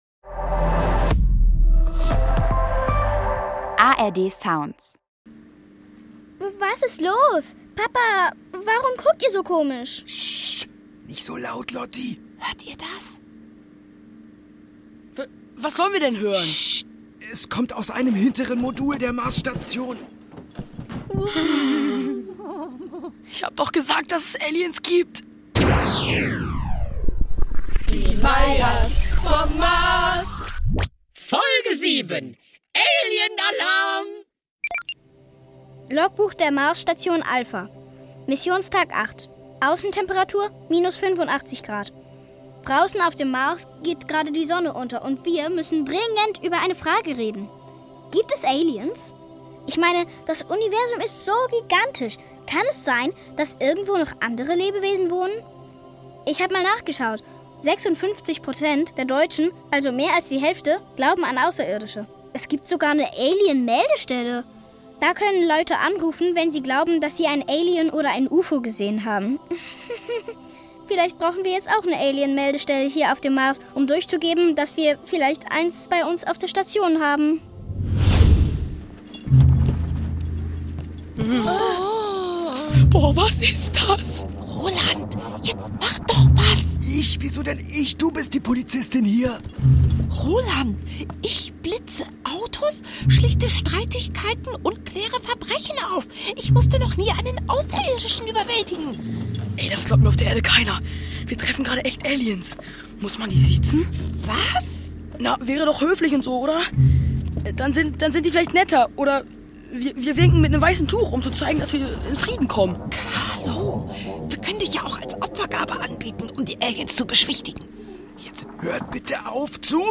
futuristische Familien-Comedy vom NDR